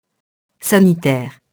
sanitaire [sanitɛr]